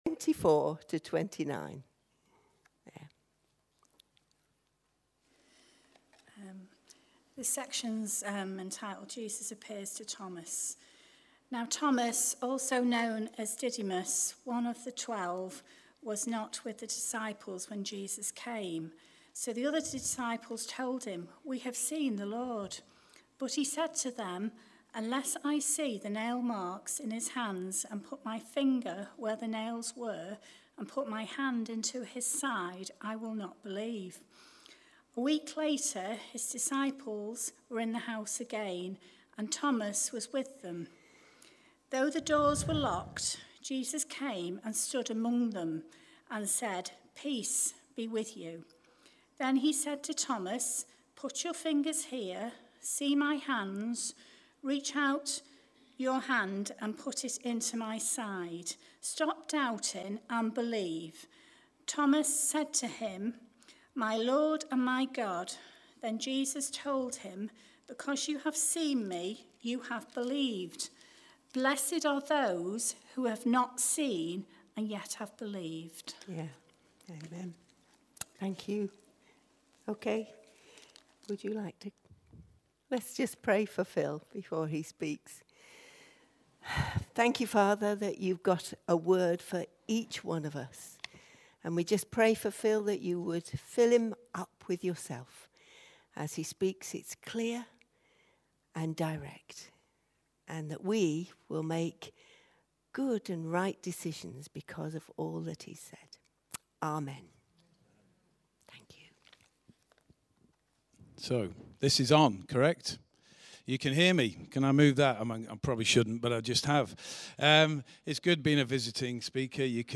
A talk from the series "Encounters with Jesus."